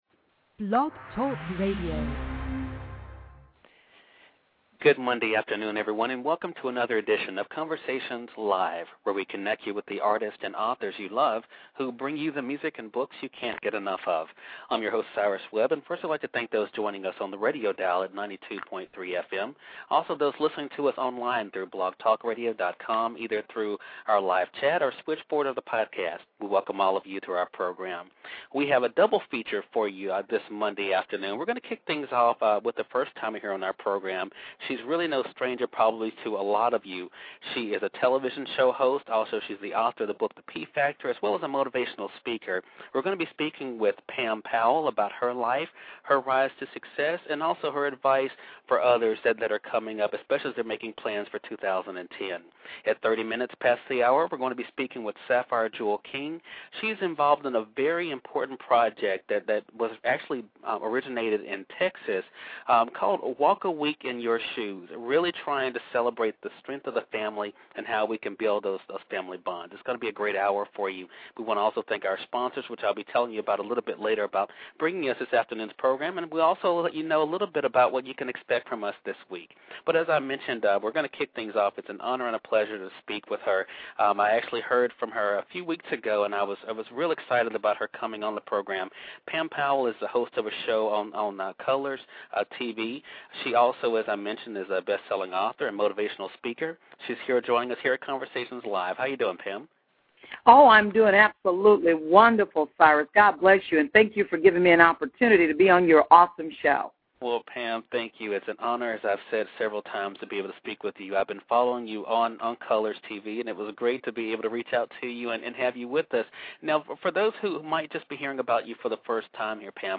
International Freedom Coalition on Conversations LIVE!My interview appears during the second half of the show but please listen to the entire show from the beginning.